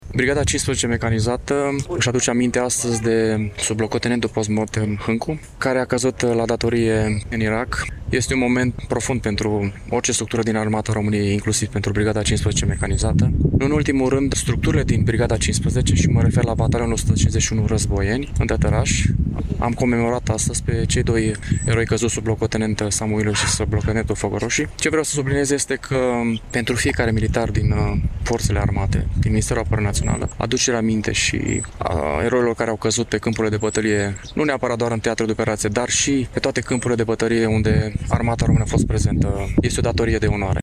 Astăzi, la Cimitirul Eterninatea din Iași a fost marcată ziua veteranilor de război și ziua armistițiului din primul Război Mondial.